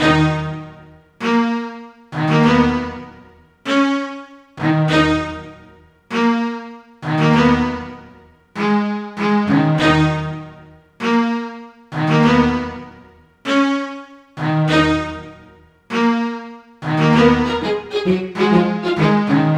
Hands Up - Ochestral Strings.wav